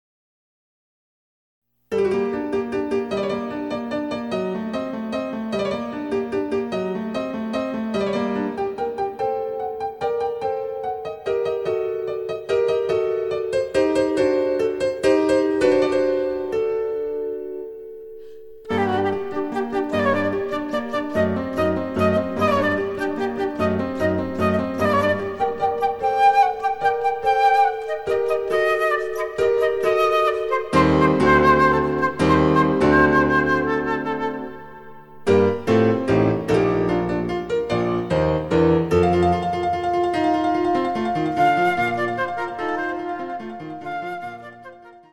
伴奏つきでフルートが演奏できる！
デジタルサンプリング音源使用